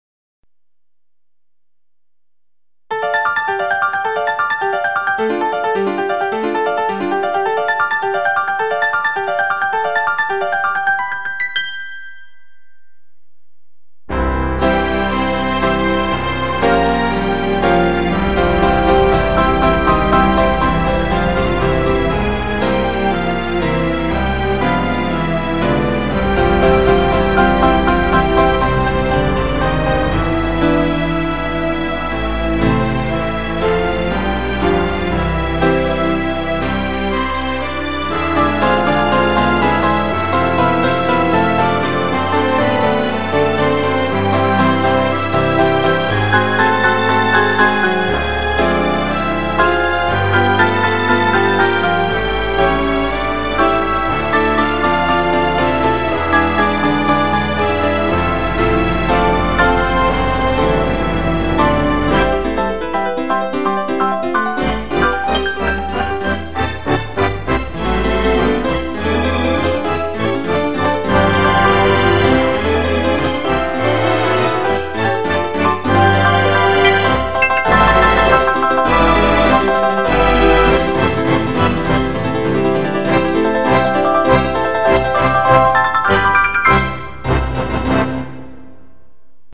Pause/Resume MP3 playback The music was created from the Zel code below. The piece comprises 18 MIDI tracks using 12 MIDI channels and it has 3670 MIDI events. The MP3 was recorded on a Roland SC-55 (in 5 takes because the polyphony was too much for this older synth).